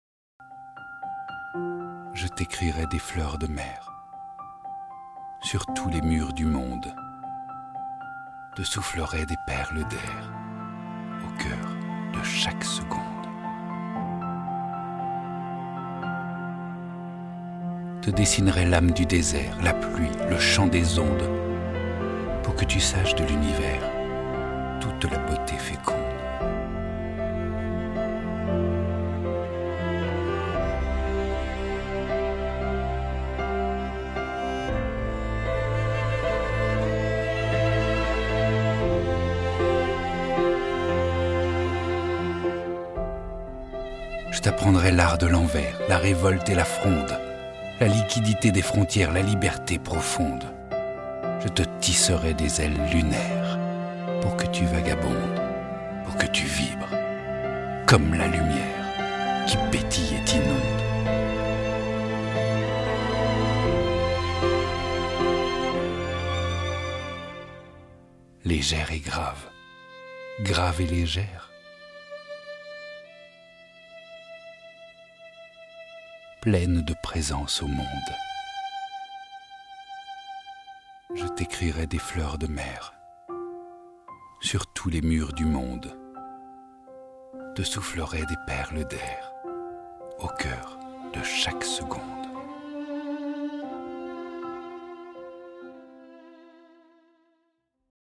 My voice is a cameleon voice.
Sprechprobe: Sonstiges (Muttersprache):